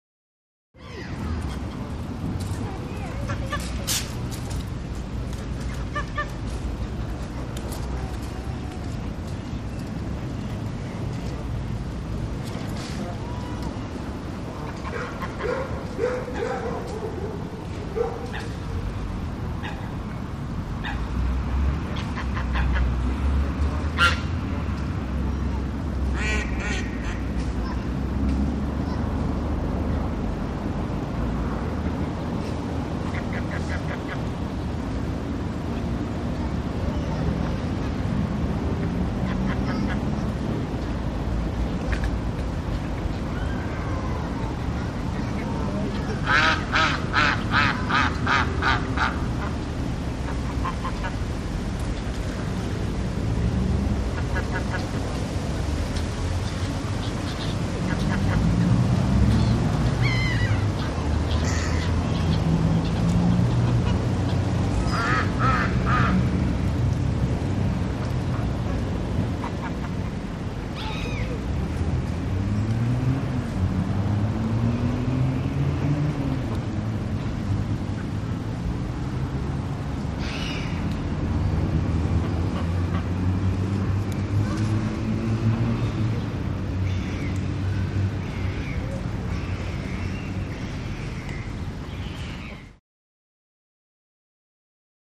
Foreign Town; Brazilian Square Atmosphere. Birds And Chickens, Light Children Talking, Traffic In Background.